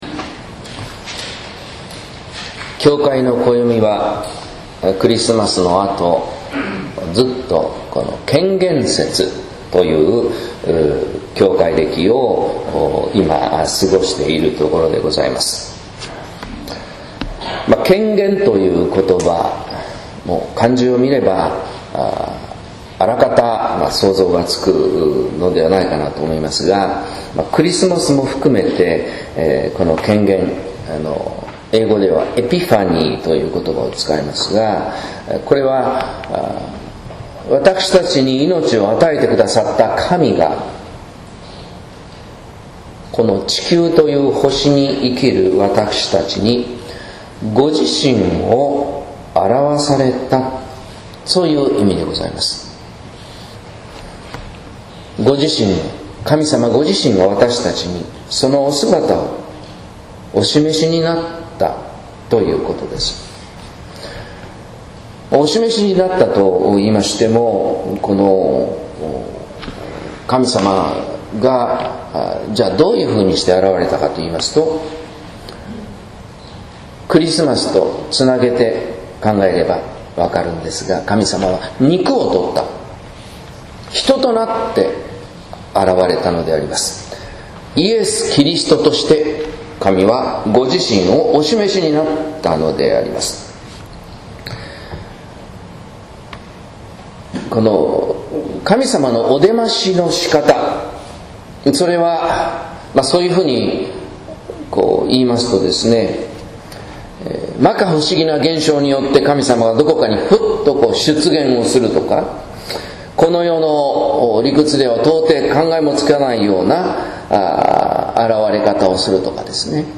説教「塩と光の人」（音声版）